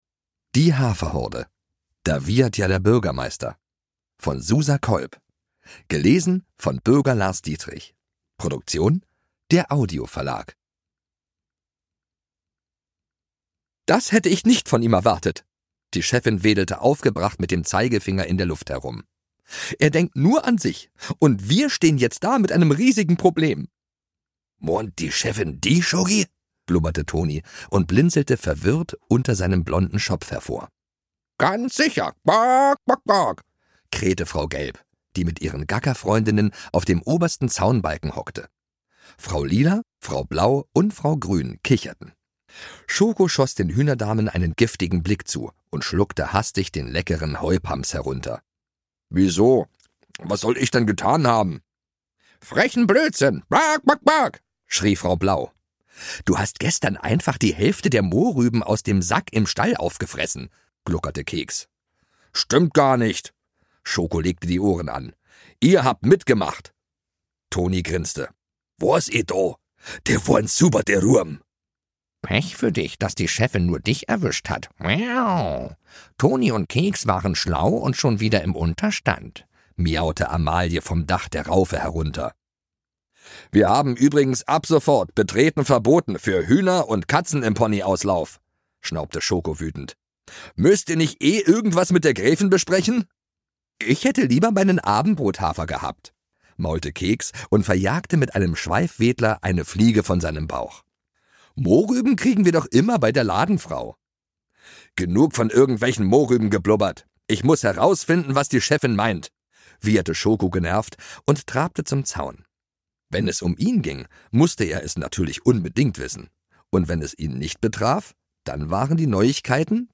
Ungekürzte Lesung mit Bürger Lars Dietrich (2 CDs)
Bürger Lars Dietrich (Sprecher)